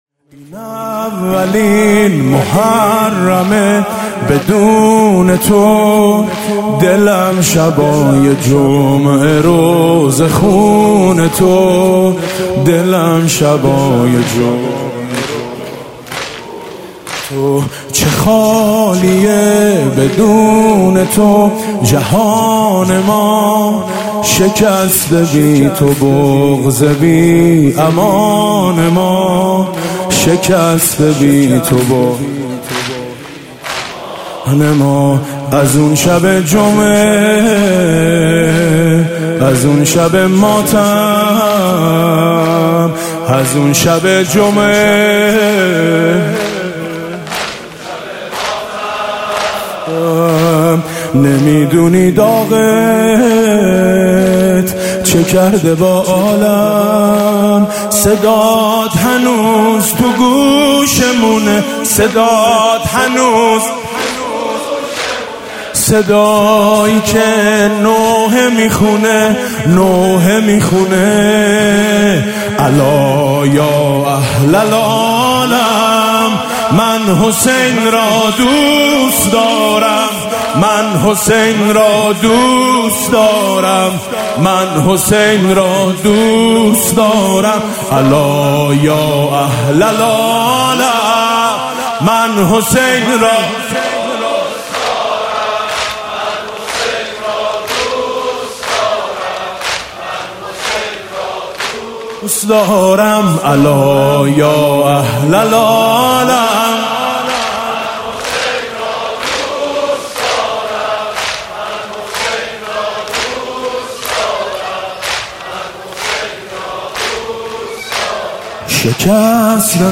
مداحی شب دوم محرم 1399 با نوای میثم مطیعی
آخرين خبر/ مداحي شب دوم محرم 1399 با نواي ميثم مطيعي، هيئت ميثاق با شهدا
زمينه- الا يا اهل العالم، من حسين را دوست دارم